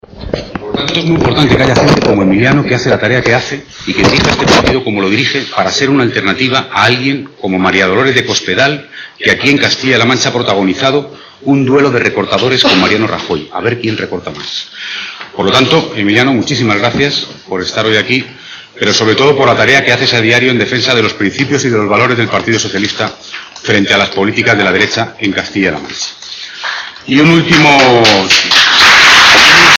López realizaba estas declaraciones durante su intervención en las jornadas “Más Europa contra la crisis” que se ha desarrollado esta mañana en Toledo.